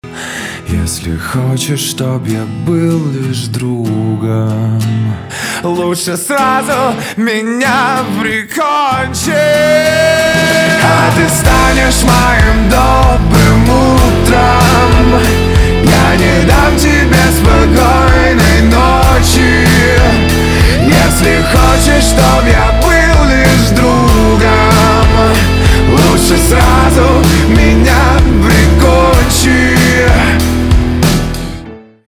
русский рок , гитара , барабаны
грустные , чувственные